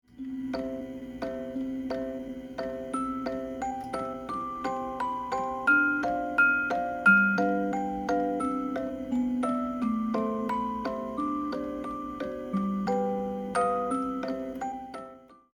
例如本次示範調整的曲子，其接近尾聲的部分，因為經過前面的音域調整，旋律和伴奏的音域會打在一起，這時我把這段旋律移高八度，低音部分不動，前後的效果比較如下：
第二個音檔旋律移高後將清晰許多。
2-高音.mp3